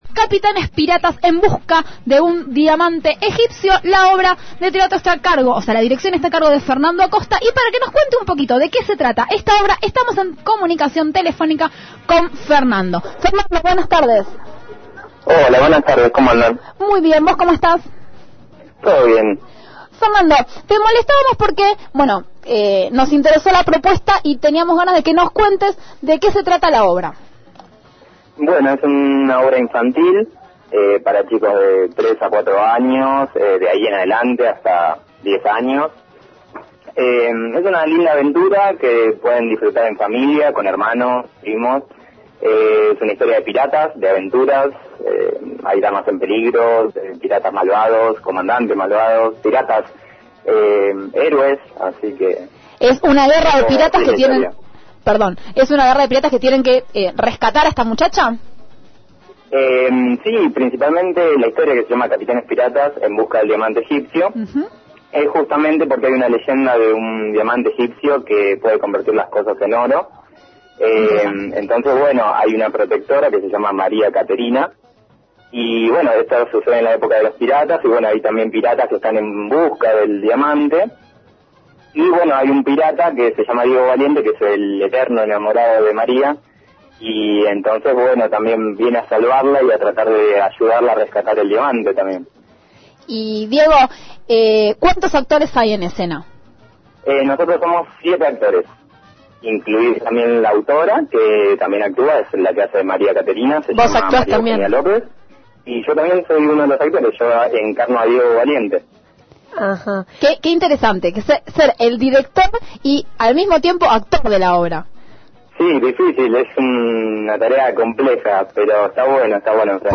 Escuchá la entrevista y disfrutá de un viaje a traves de la imaginación, de la magia, el amor y la lucha de piratas.